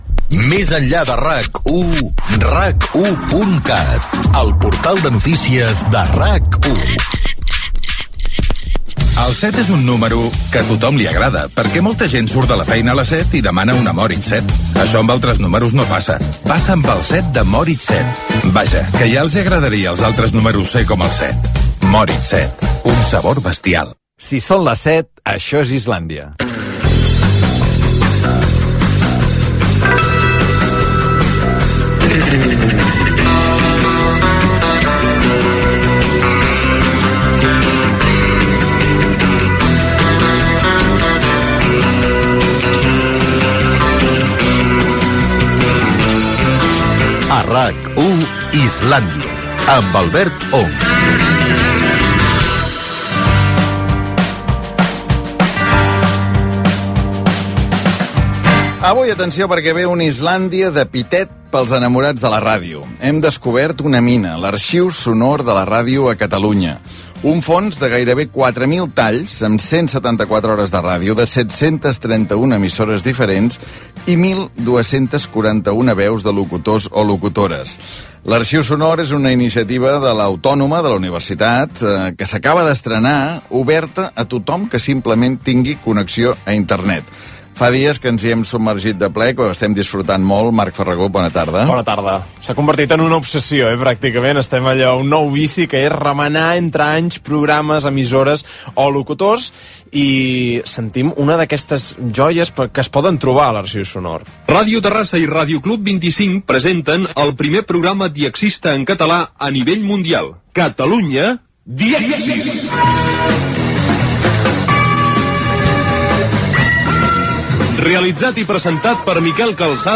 Promoció del portal de RAC 1, publicitat, careta del programa, presentació de l'Arxiu Sonor de la Ràdio a Catalunya de la UAB, amb fragments sonors dels inicis de Miquel Calçada, cursos de català de Ràdio Barcelona (1969),Joaquín Soler Serrano a les riuades del Vallès de 1962.
Entreteniment